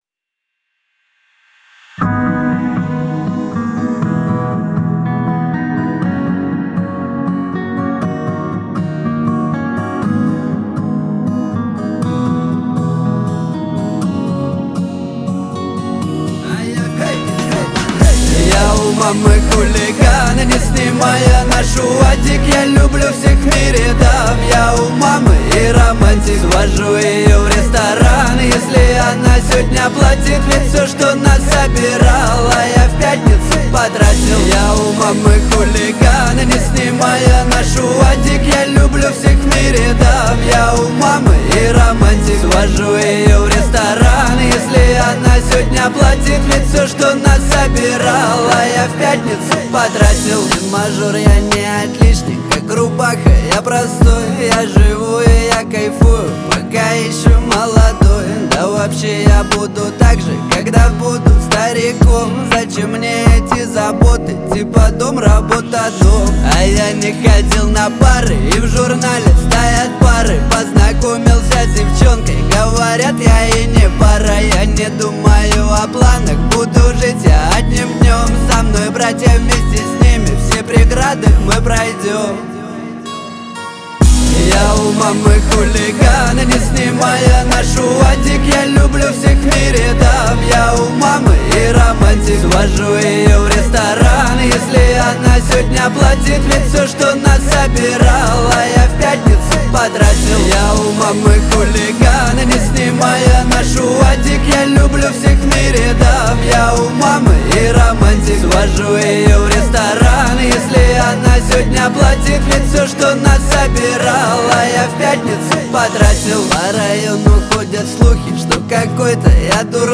Жанр: Русские народные песни